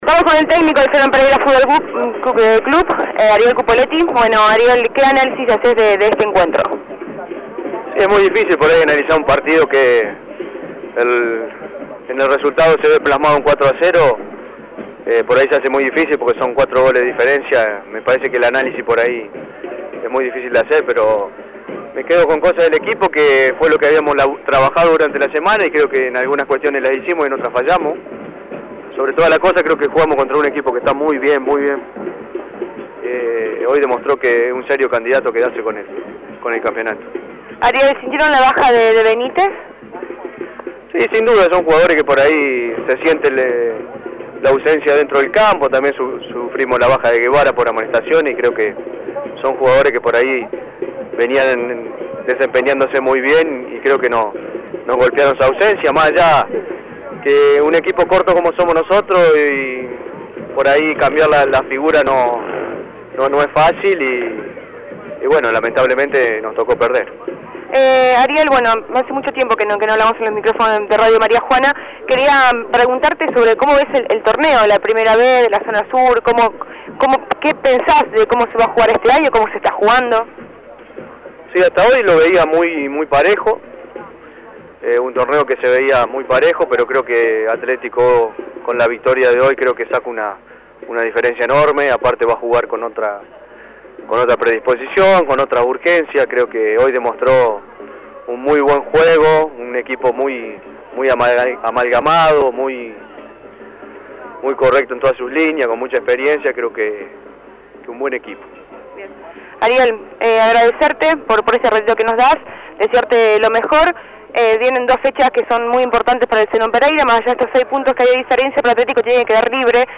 Show Deportivo